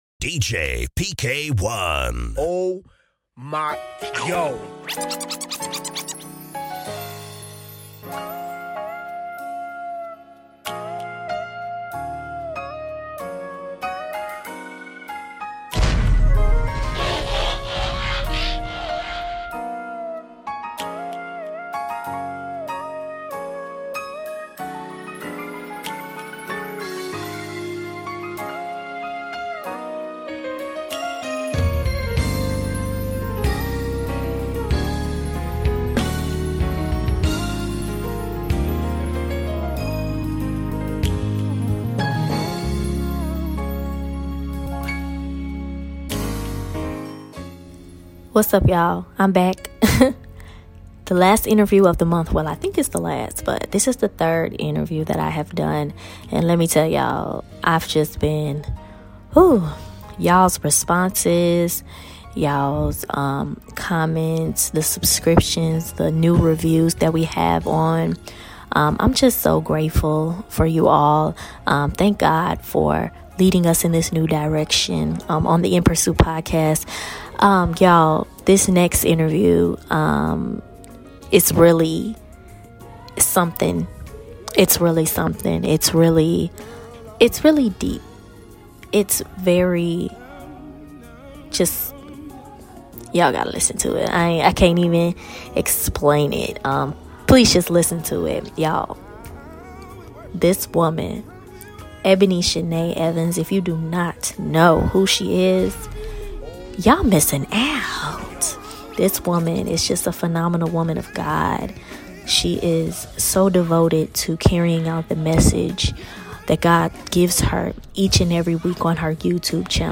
This interview right here.